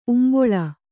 ♪ umboḷa